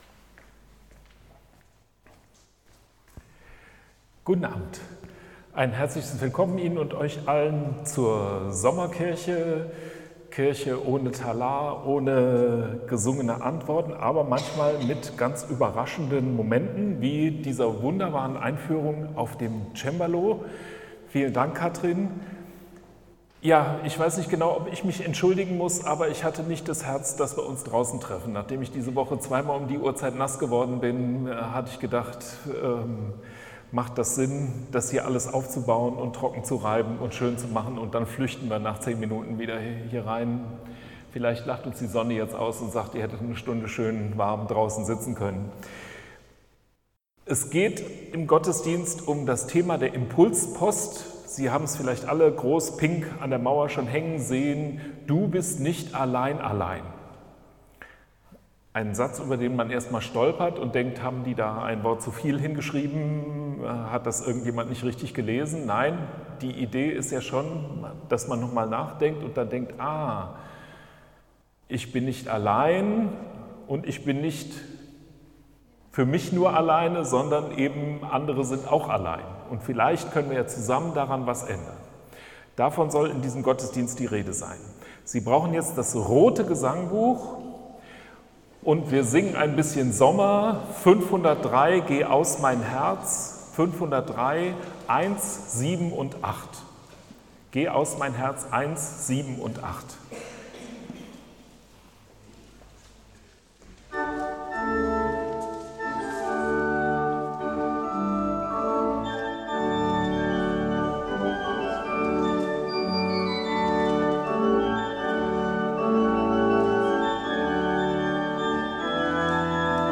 Sommerkirche vom 26.07.2025 als Audio-Podcast Liebe Gemeinde, herzliche Einladung zur Sommerkirche am 26. Juli 2025 in der Martinskirche Nierstein als Audio-Podcast.